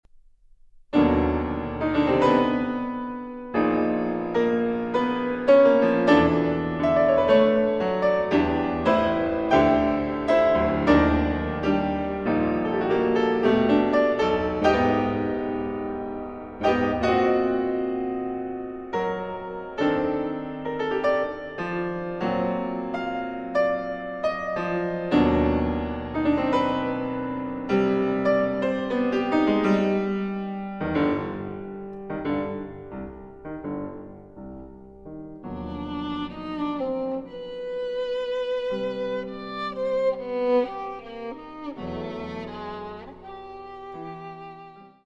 Sonata for Violin and Piano (1963) (22:57)
Lento (quasi recitativo) (4:43)